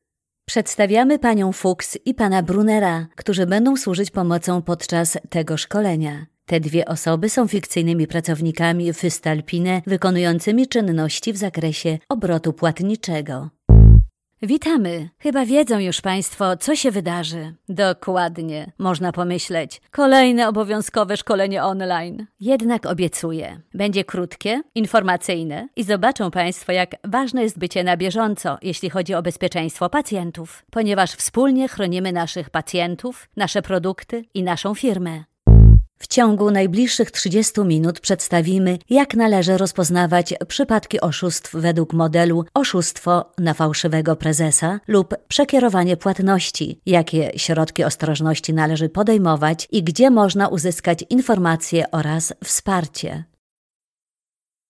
Sprecherin polnisch und Schauspielerin.
Sprechprobe: eLearning (Muttersprache):
I´m a professional native polish actress voice talent.